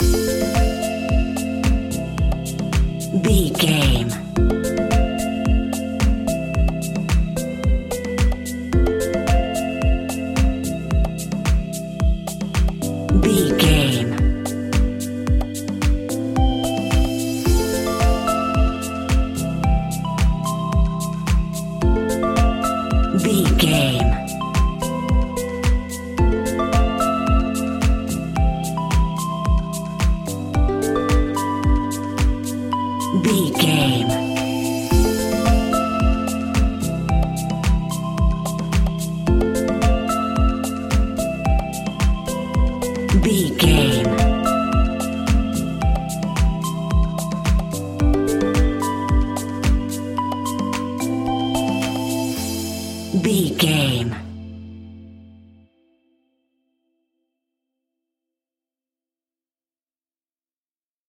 Aeolian/Minor
groovy
peaceful
tranquil
meditative
smooth
drum machine
synthesiser
house
electro house
funky house
synth leads
synth bass